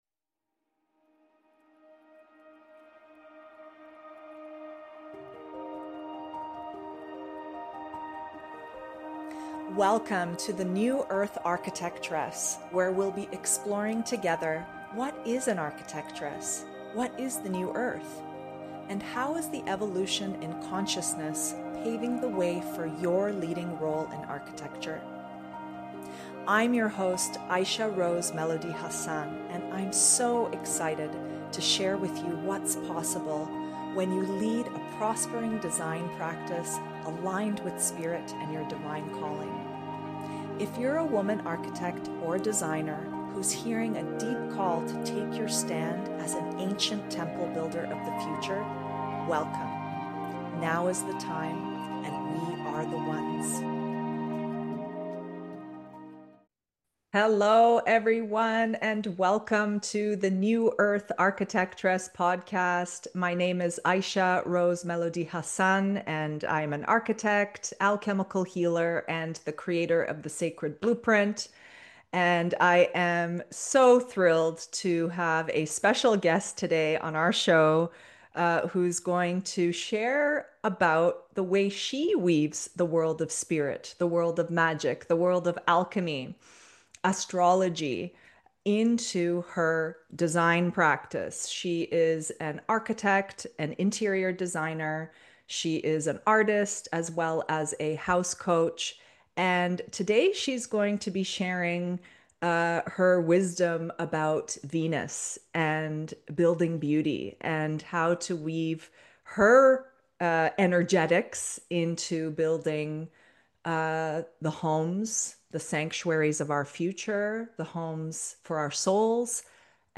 In this luminous conversation